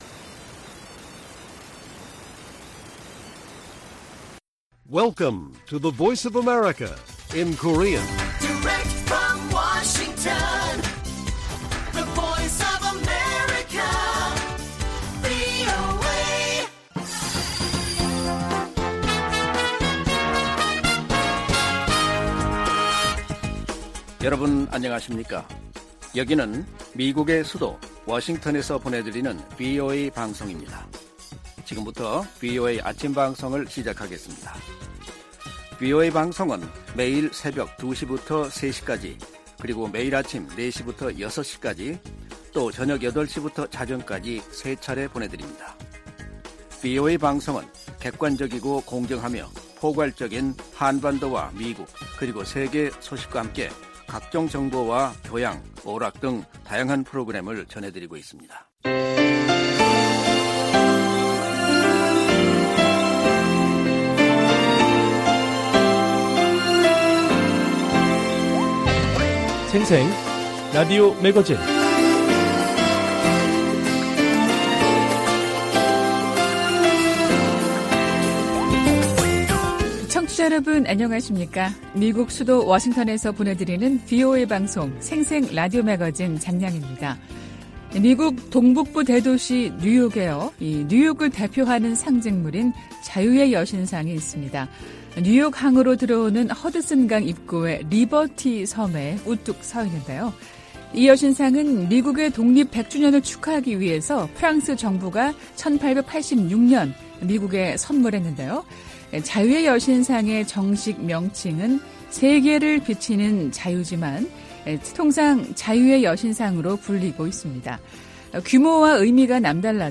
VOA 한국어 방송의 월요일 오전 프로그램 1부입니다.